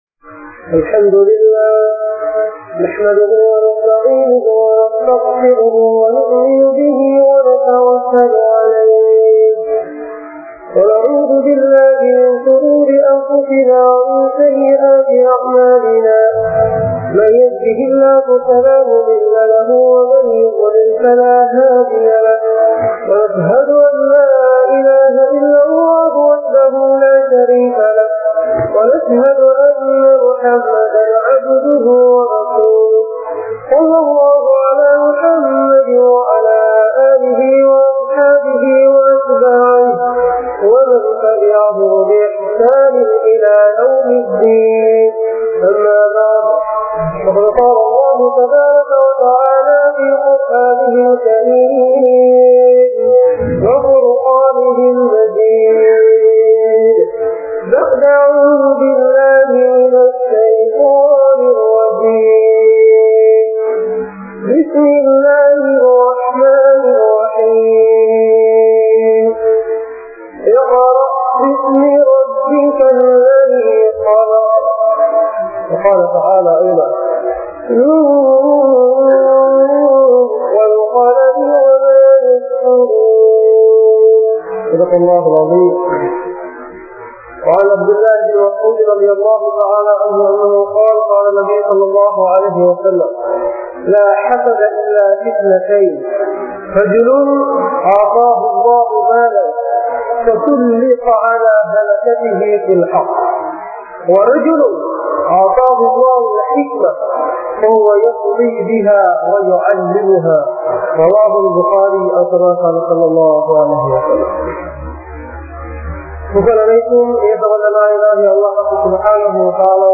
Kalvi Katpathan Avasiyam (கல்வி கற்பதன் அவசியம்) | Audio Bayans | All Ceylon Muslim Youth Community | Addalaichenai